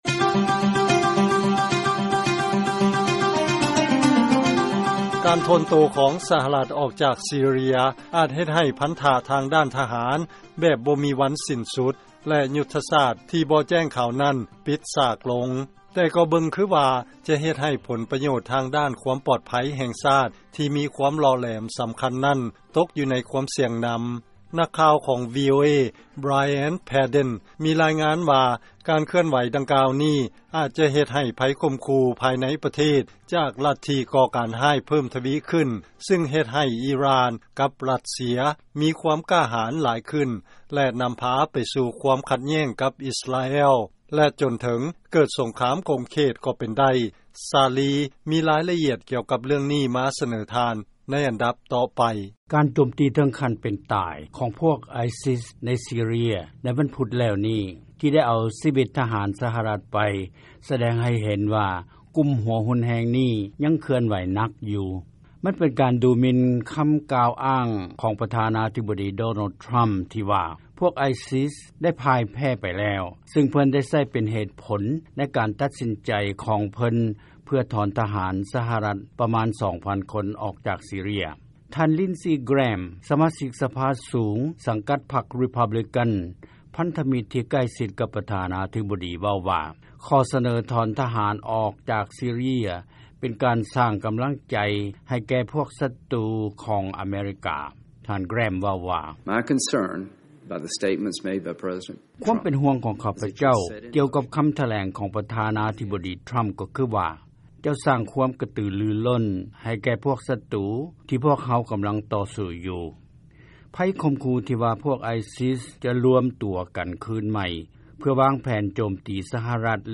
ເຊີນຟັງລາຍງານກ່ຽວກັບຊີເຣຍ